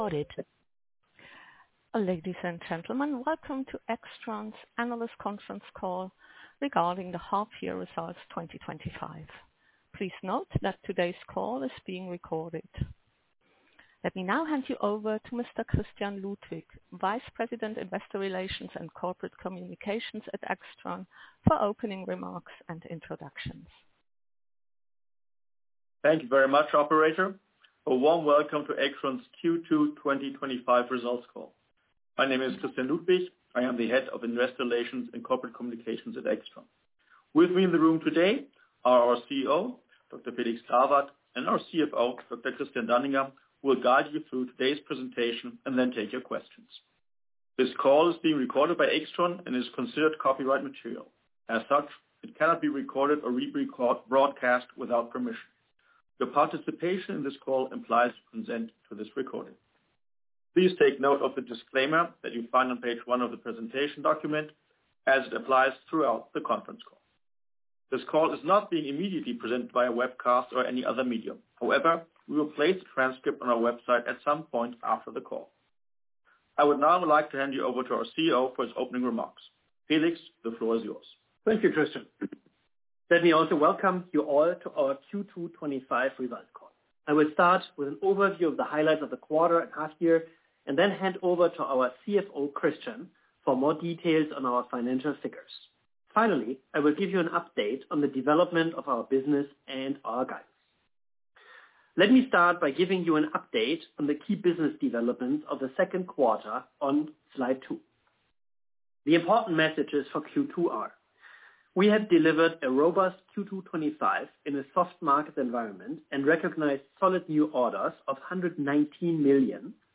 In conjunction with the release of the H1/2025 Results AIXTRON held a conference call (in English) for analysts and investors on Thursday, July 31, 2025 at 3:00 pm (CEST), 06:00 am (PDT), 09:00 am (EDT).